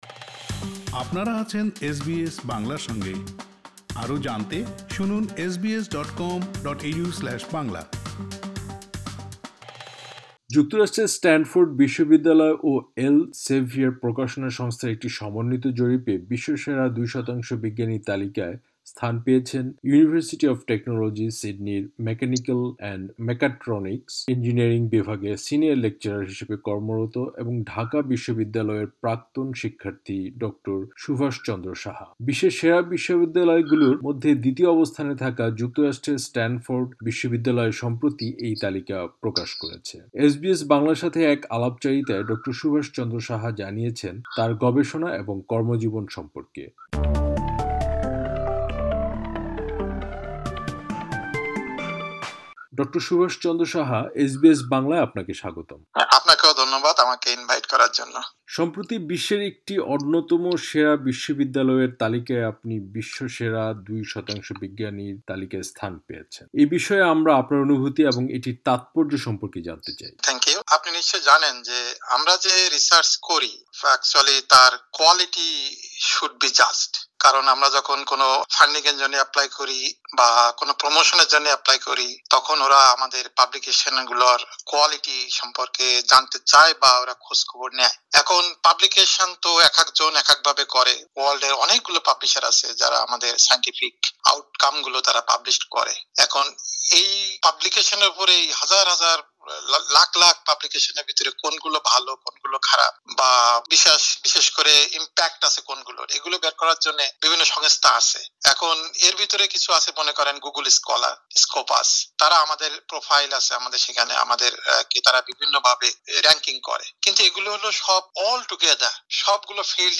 এসবিএস বাংলার সাথে এক আলাপচারিতায়